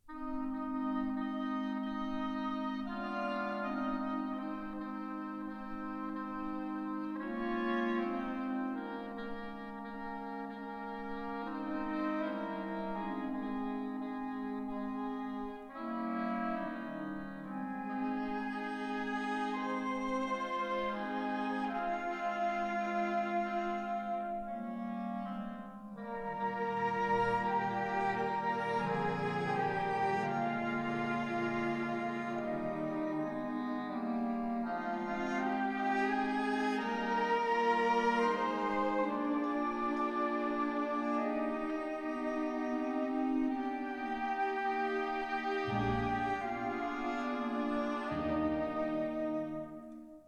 Stereo recording made in London